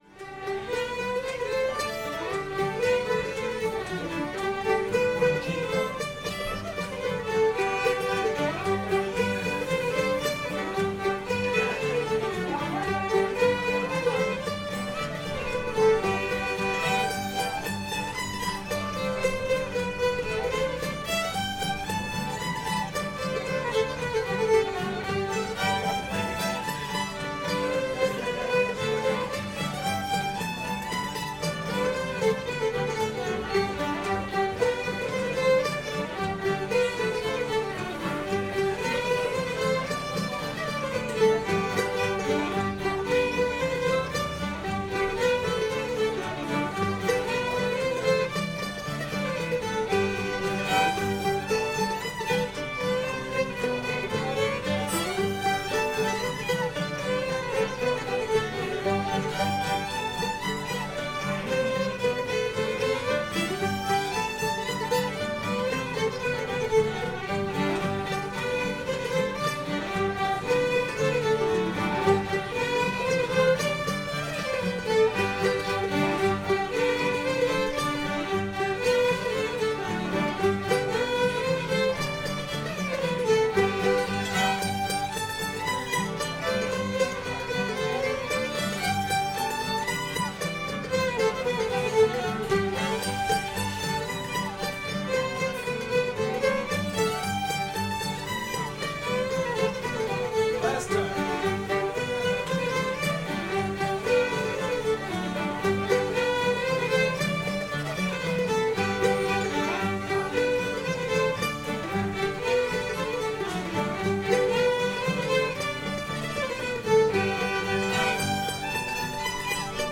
shoes and stockings [G]